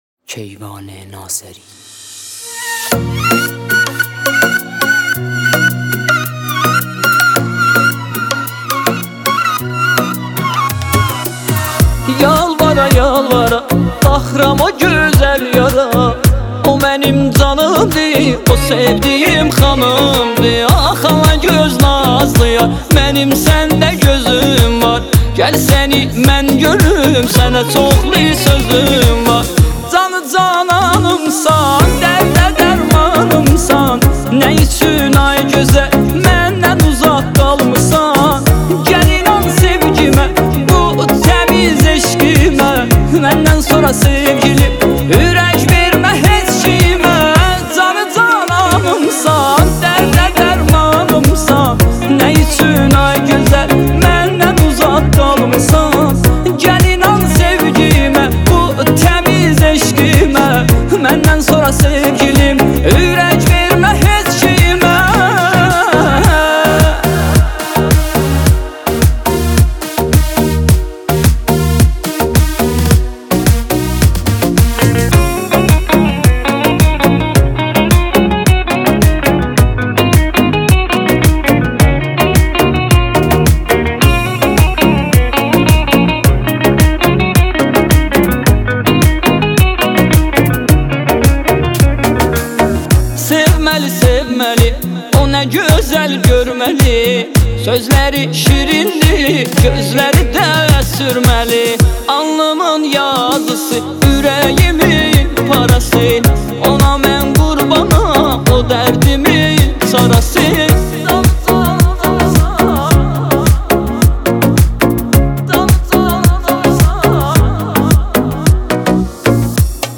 آهنگ ترکی معروف اینستا